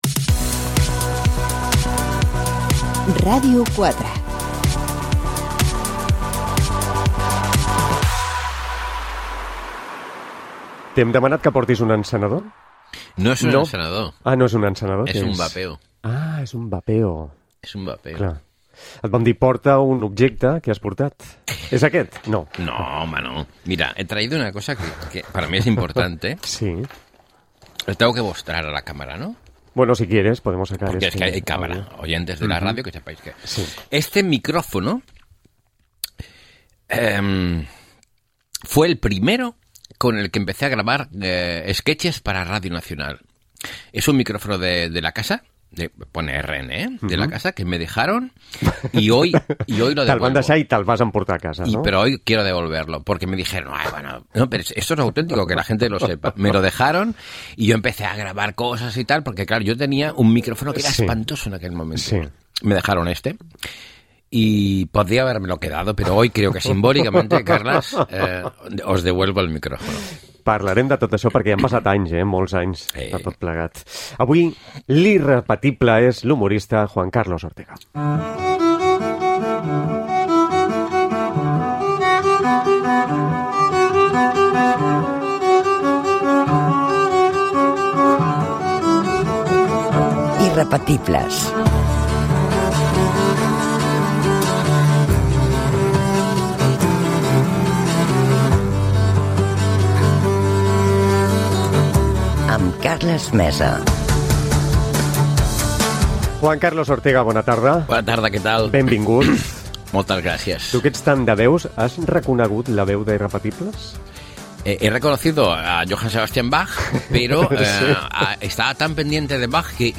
Careta del programa (veu Olga Viza). Entrevista a Juan Carlos Ortega sobre la seva trajectòria professional, vida peronal i com fa i munta els seus espais radiofònics humorístics.